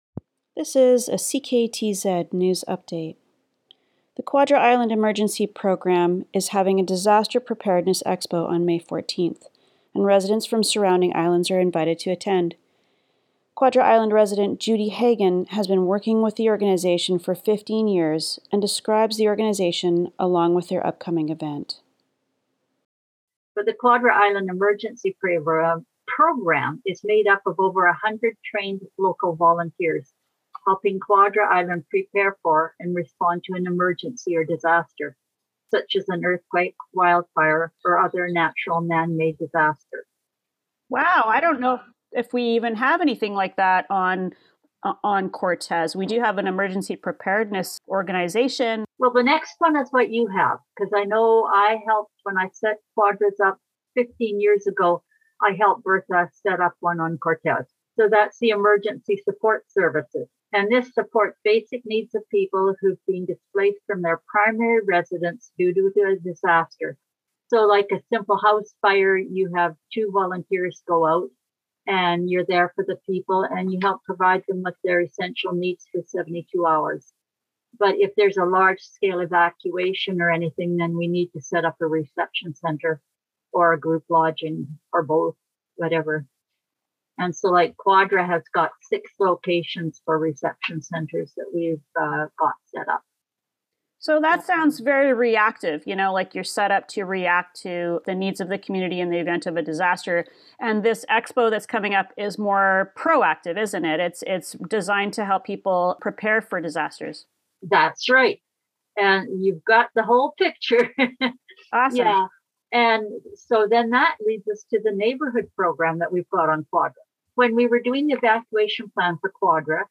CKTZ-News-Quadra-Emergency-Preparedness-Expo.mp3